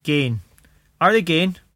[gayn: ar thee gayn]